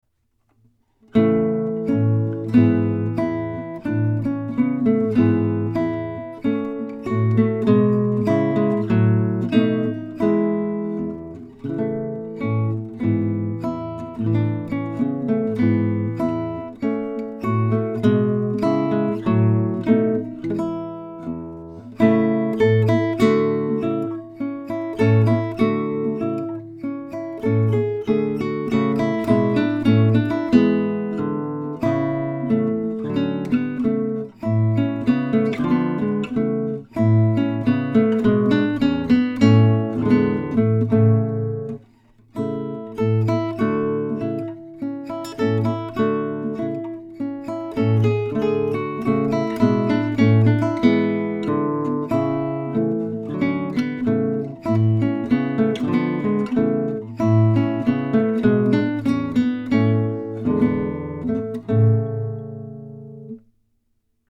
Strunal 4855 klasická gitara
Vrchná doskacedar
Zvukové skúšky